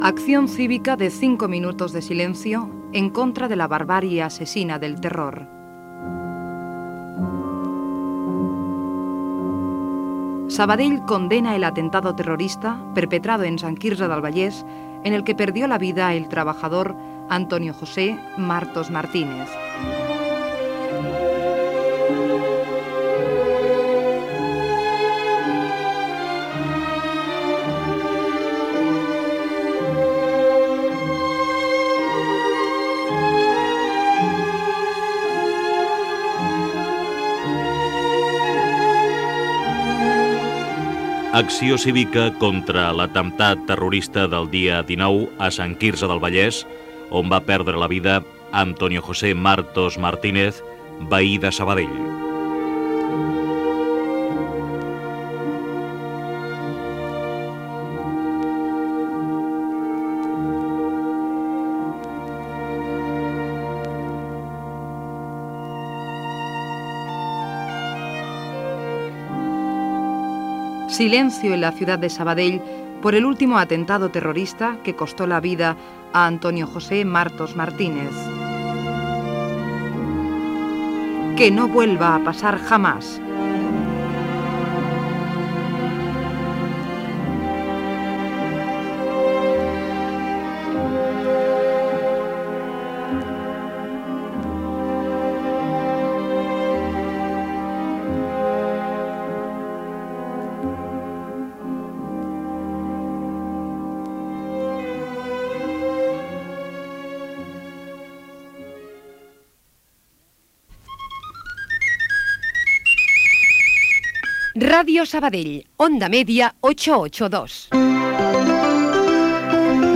Cinc minuts de silenci per l'atemptat terrorista d'ETA del 19 de març de 1992 a Sant Quirze del Vallès, on va resultar mort un obrer de la construcció veí de Sabadell.
Connexió amb la unitat mòbil.
Declaracions de l'alcalde de Sabadell, Antoni Farrés.
Informatiu